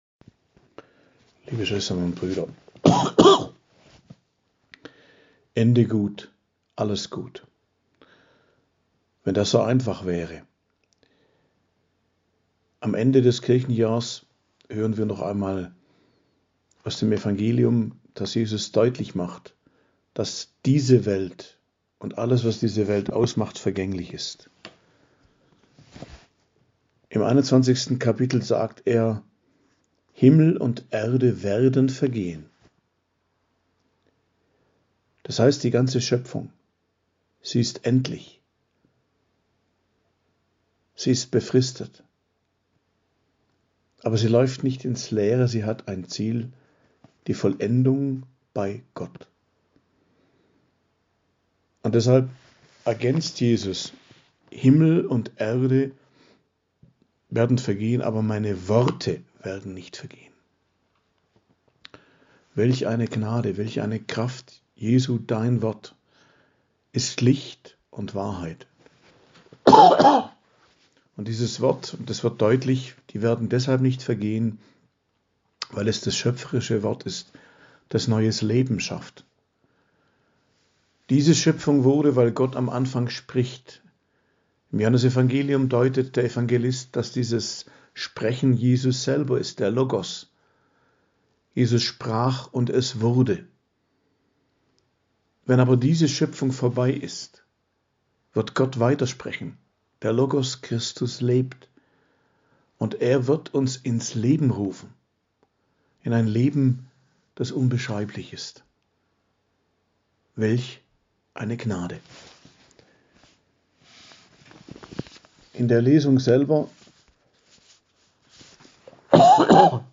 Predigt am Freitag der 34. Woche i.J., 25.11.2022